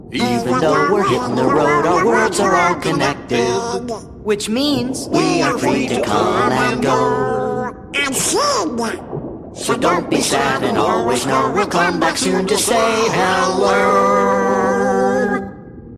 Cutscene audio